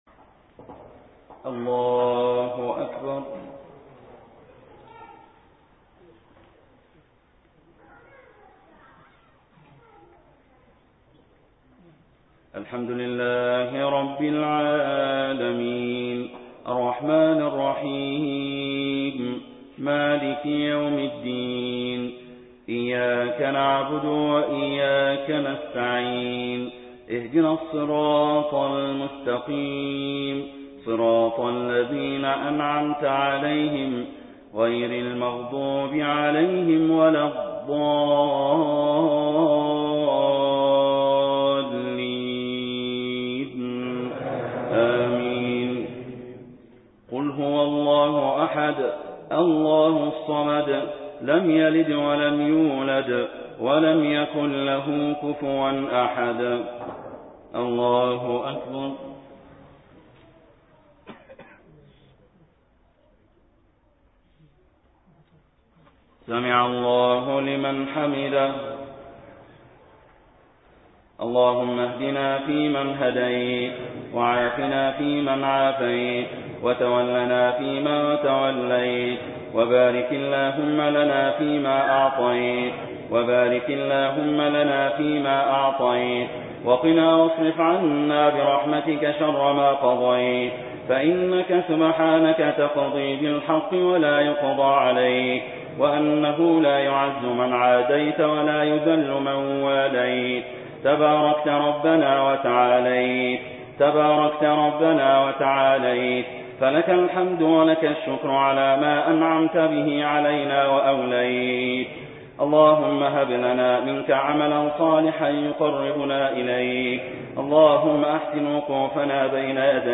دعاء القنوت في صلاة الوتر